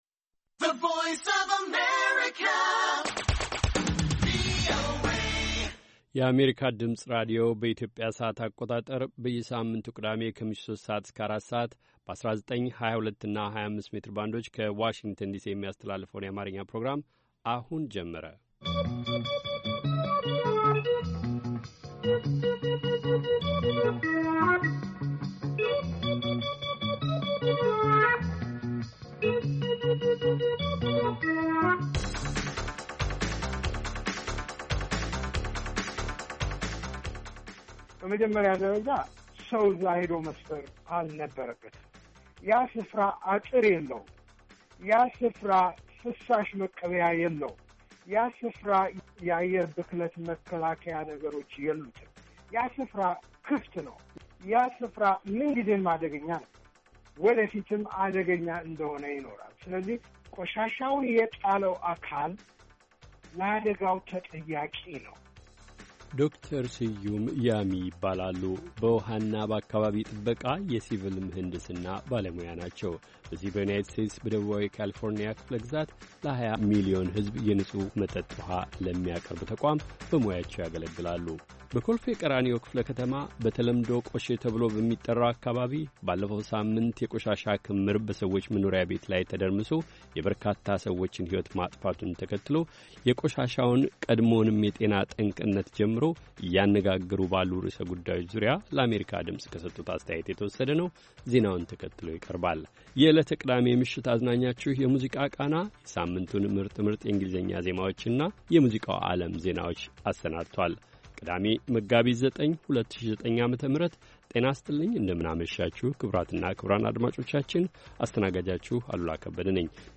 ቅዳሜ፡-ከምሽቱ ሦስት ሰዓት የአማርኛ ዜና